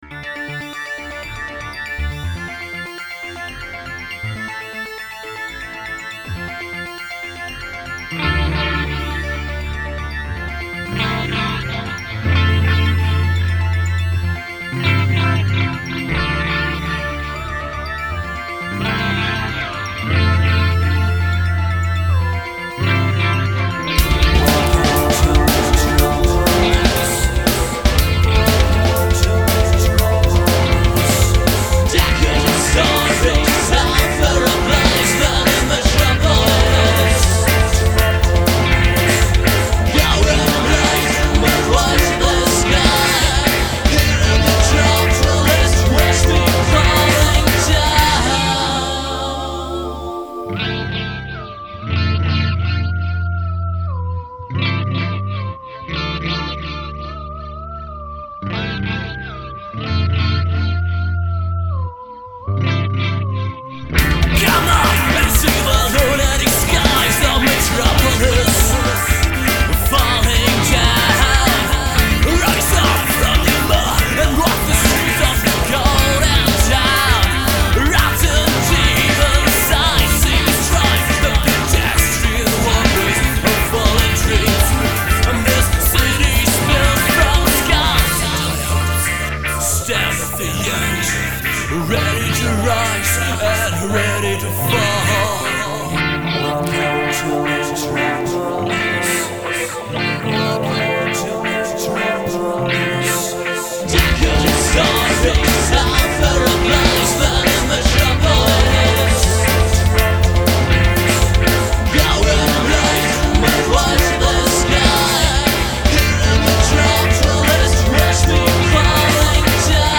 Gesang, Gitarre, Keyboard, Bass, Programming